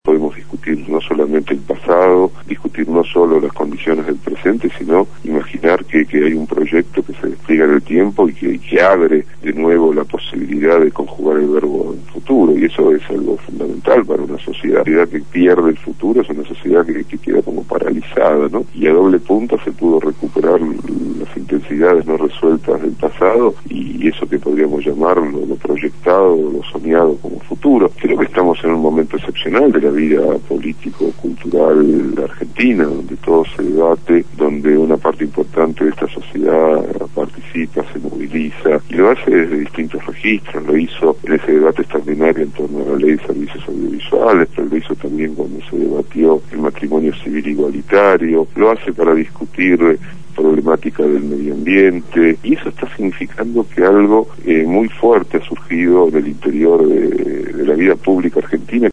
Ricardo Forster fue entrevistado en el programa «Punto de Partida» (lunes a viernes de 7 a 9 de la mañana) habló sobre el contexto histórico- político del golpe de estado cívico militar del 24 de marzo de 1976 y sobre el significado social en la memoria colectiva que tiene esta fecha en el año 2011.